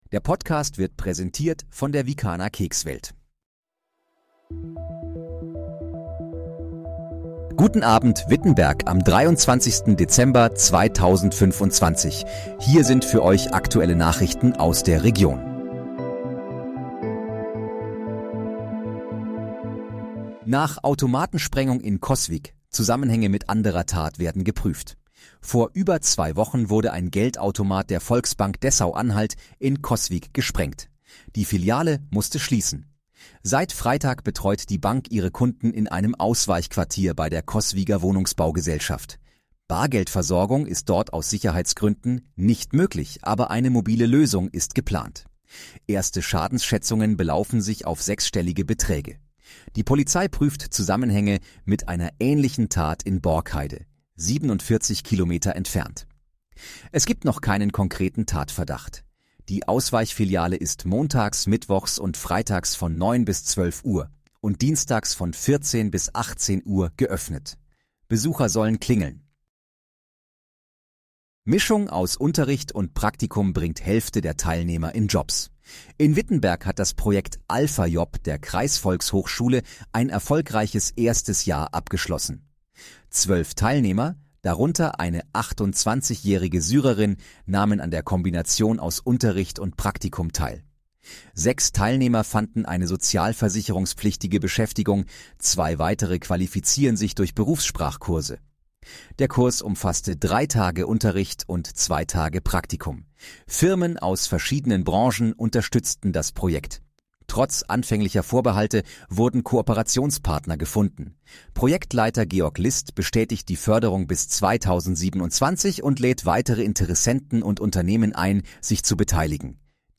Guten Abend, Wittenberg: Aktuelle Nachrichten vom 23.12.2025, erstellt mit KI-Unterstützung
Nachrichten